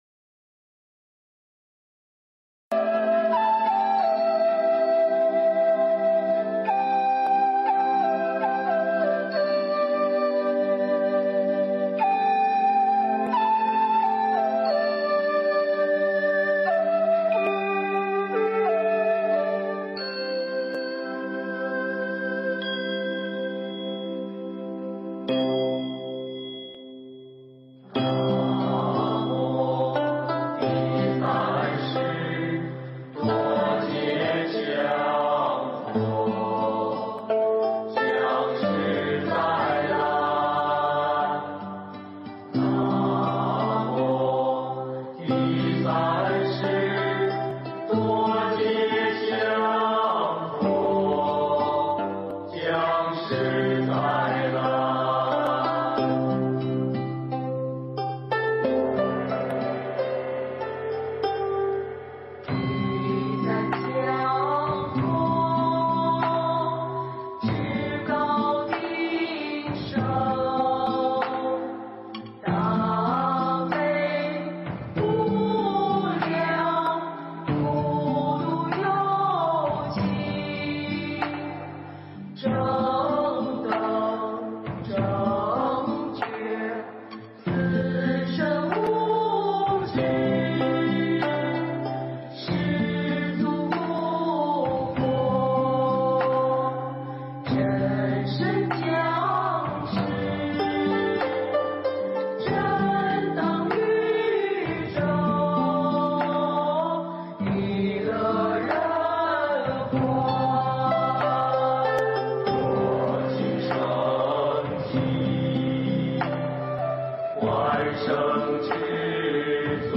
如来正法 永放光芒 【佛教音乐】